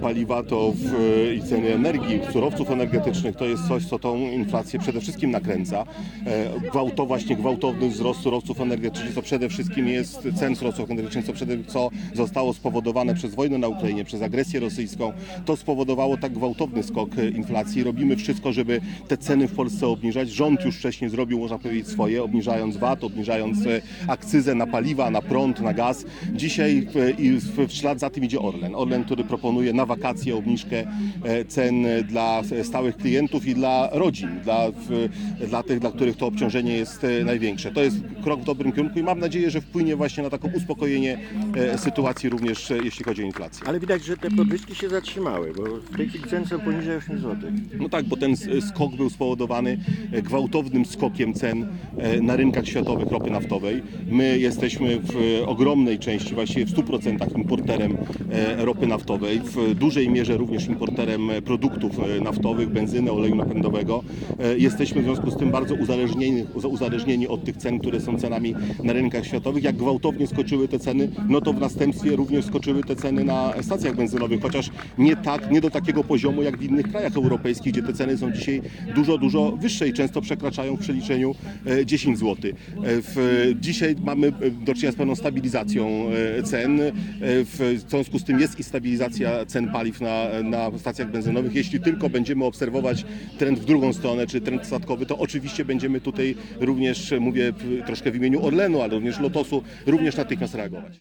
- Mam nadzieję, że wakacyjna obniżka cen paliw na stacjach Orlenu wpłynie na obniżenie inflacji - powiedział w rozmowie z Radiem Łódź Jacek Sasin.